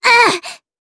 Cecilia-Vox_Damage_jp_02.wav